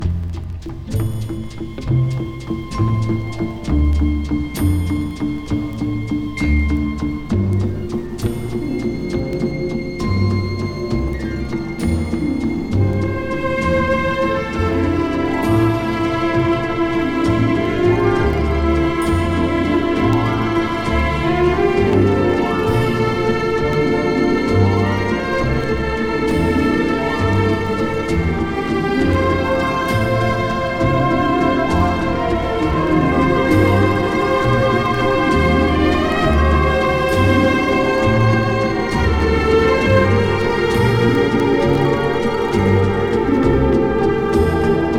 Jazz, Pop, Easy Listening　UK　12inchレコード　33rpm　Mono/Stereo